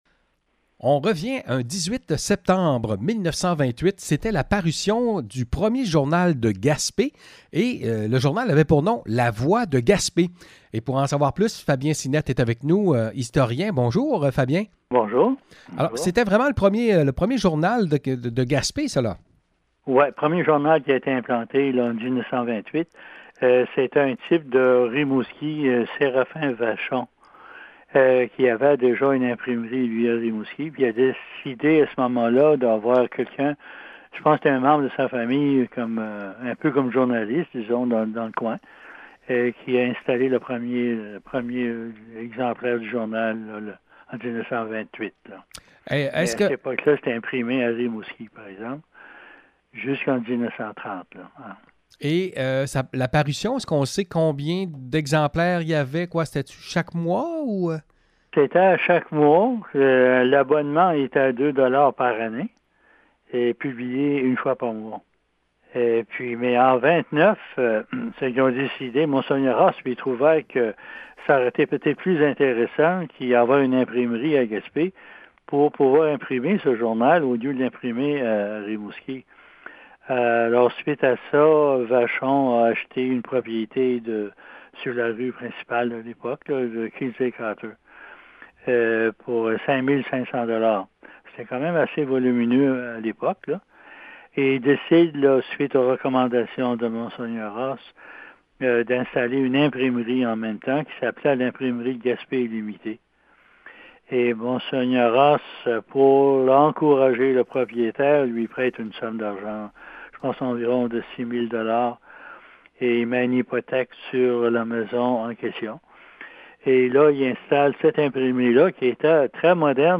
En 1928 voyait le jour le premier journal à Gaspé sous le nom de « La voix de Gaspé ». Entrevue